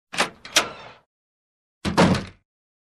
На этой странице собраны реалистичные звуки ручки двери: скрипы, щелчки, плавные и резкие повороты.
Звук открывания и захлопывания двери